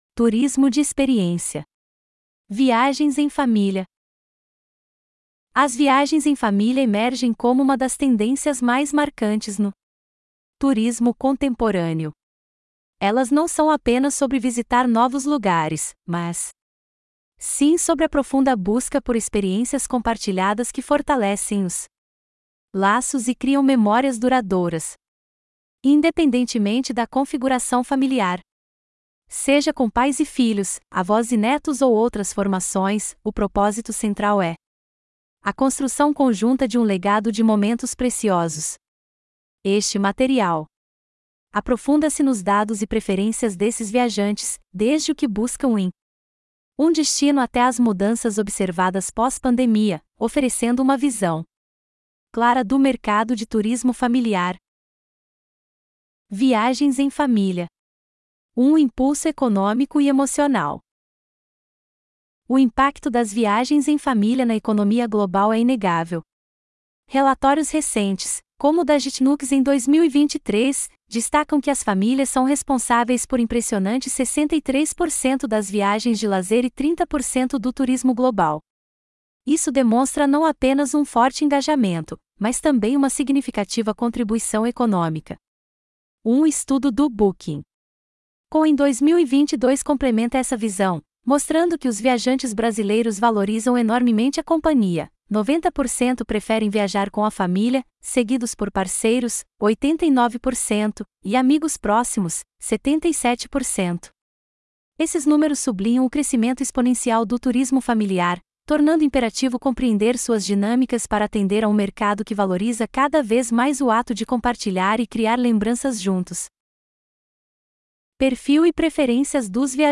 Audiobook-Turismo-de-Experiência-Viagens-em-Família.mp3